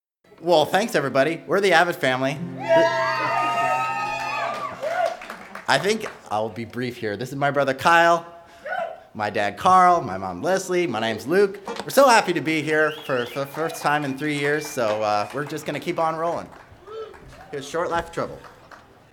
Recorded live at the Good Old Fashioned Bluegrass Festival in Tres Pinos, CA, over two performances in 2010 and 2011.
Band Introductions